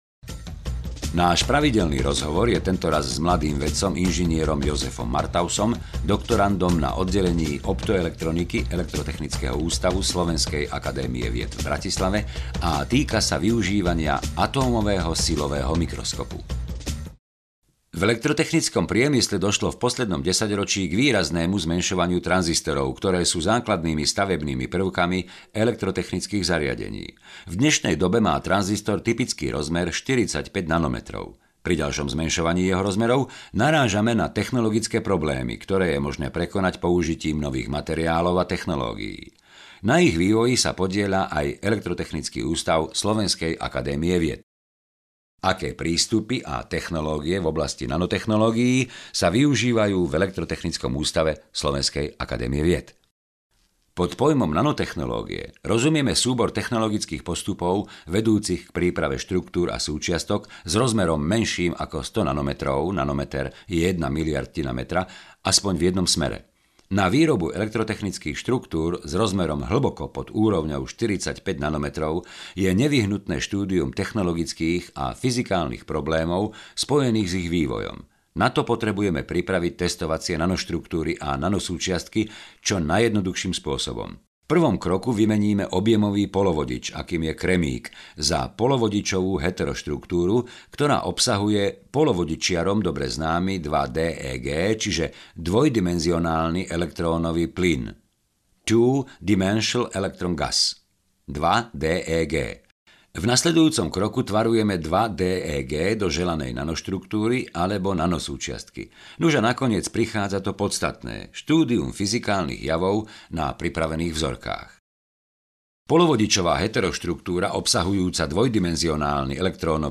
Bezplatné hodinové čítanie z aktuálneho čísla časopisu Quarku je opäť tu! Tím rozhlasových profesionálov pre vás pripravil ďalšie zvukové číslo obľúbeného časopisu.